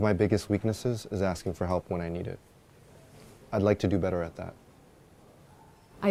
male_audio.mp3